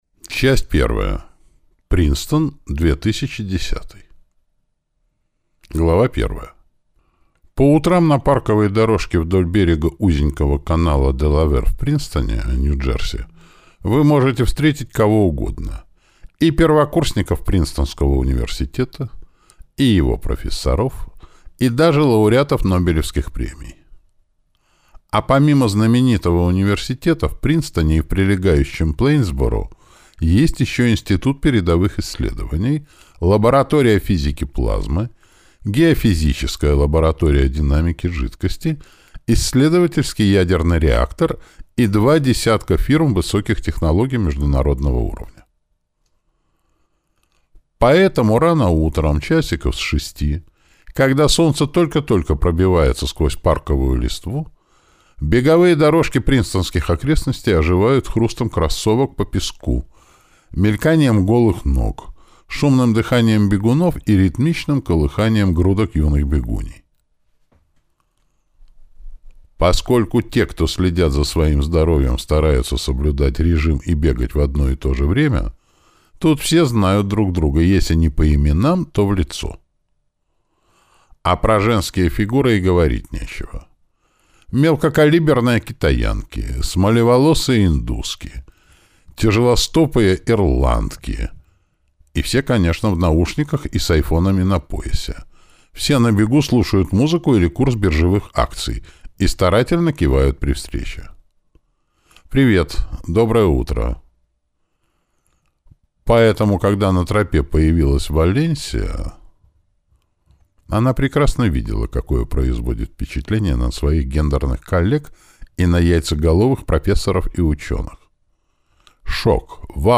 Аудиокнига 18+, или Последний аргумент | Библиотека аудиокниг